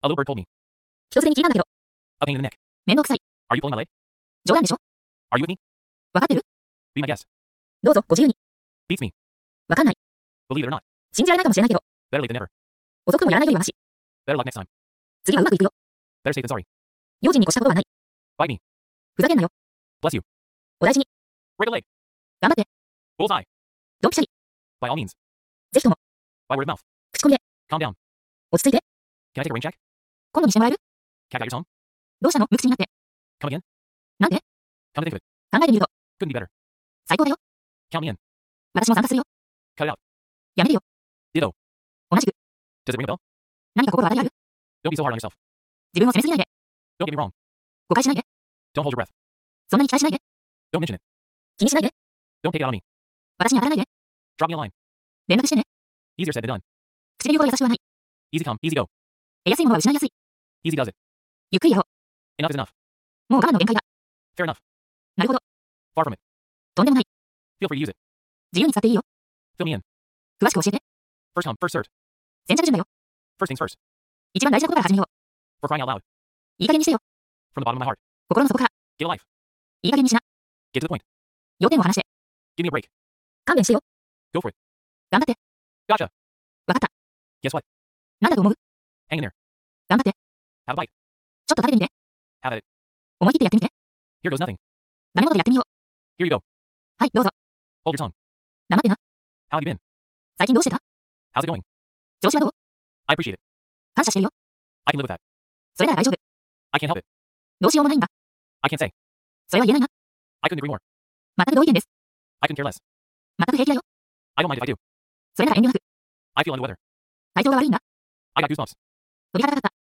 ～ 417Hzソルフェジオ周波数バックグランド音楽で右脳も超活性！
この動画では、ネイティブがスピーディーな会話表現に使うフレーズを200厳選してお届けします。
さらに、今回はバックグランドミュージックに、マイナス思考やマイナスな状況から回復を促すと話題の417Hzソルフェジオ周波数の音源と、小川のせせらぎのBGMを付け加えています。